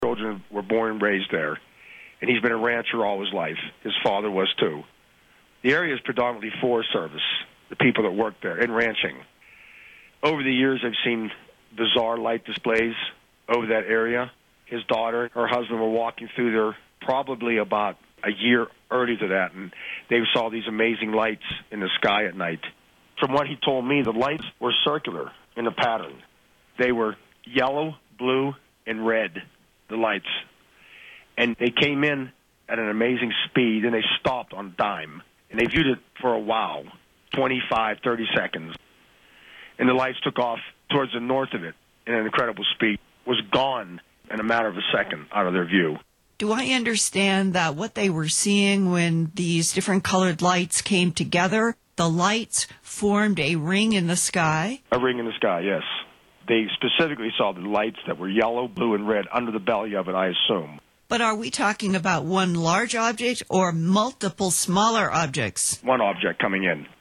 Interview on Alien Cattle Mutilations 1